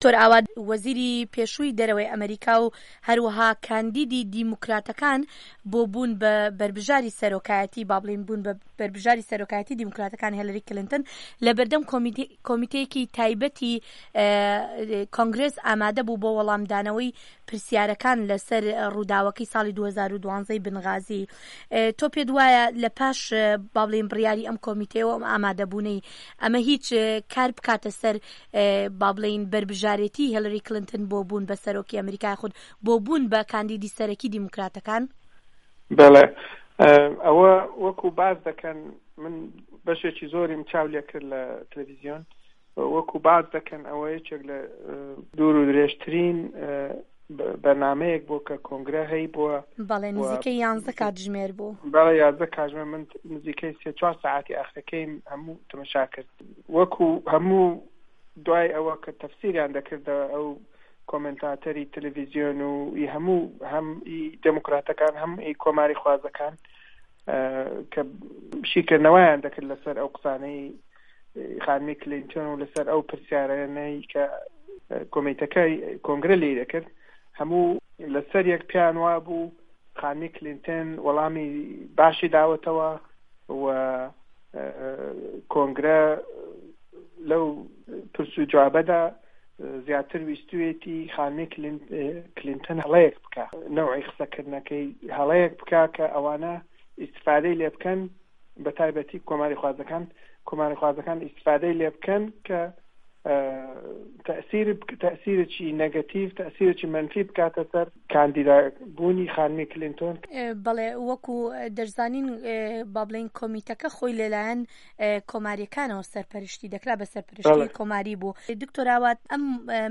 دەقی وتووێژەکە